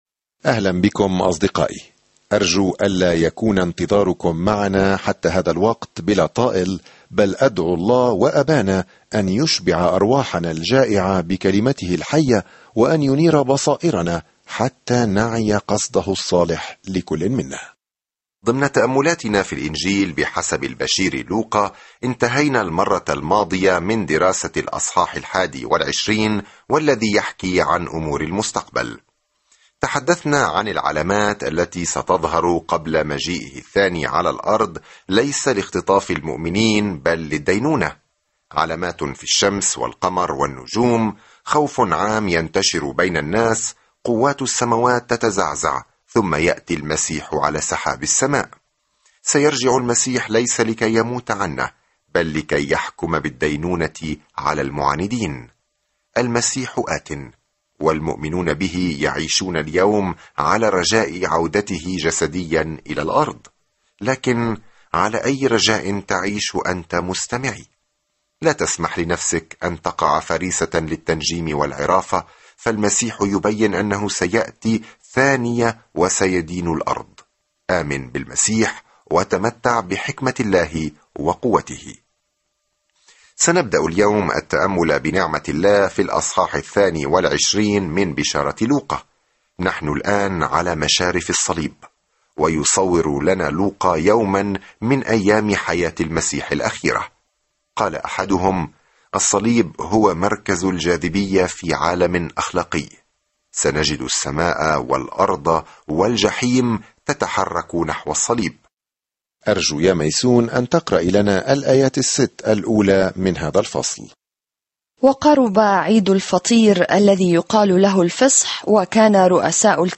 الكلمة لُوقَا 1:22-34 يوم 25 ابدأ هذه الخطة يوم 27 عن هذه الخطة تابع رحلتك عبر الكتاب المقدس بخير باستخدام خطة الدراسة الصوتية للوقا، التالية في الستار: ابدأ الآن!ينقل شهود العيان الأخبار السارة التي يرويها لوقا عن قصة يسوع منذ الولادة وحتى الموت وحتى القيامة؛ ويعيد لوقا أيضًا سرد تعاليمه التي غيرت العالم. سافر يوميًا عبر لوقا وأنت تستمع إلى الدراسة الصوتية وتقرأ آيات مختارة من كلمة الله.